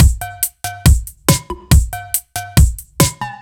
Index of /musicradar/french-house-chillout-samples/140bpm/Beats
FHC_BeatD_140-03.wav